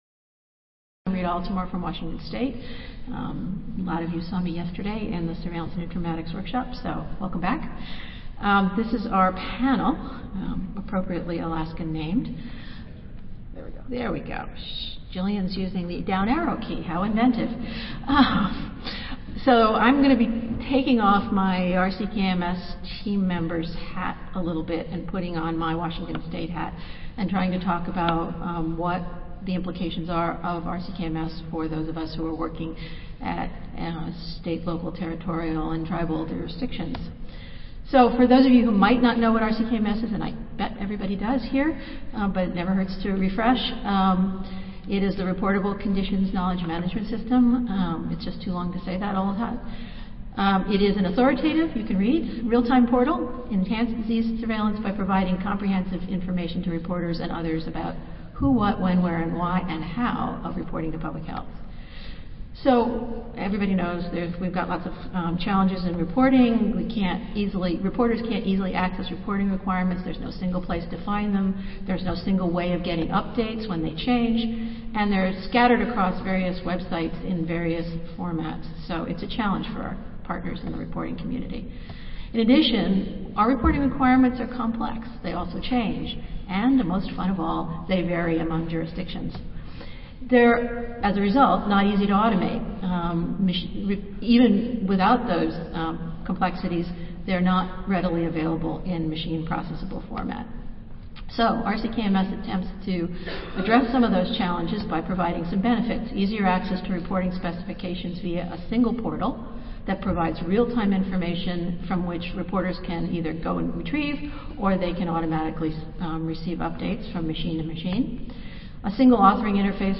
Tikahtnu C&F, Dena'ina Convention Center
Recorded Presentation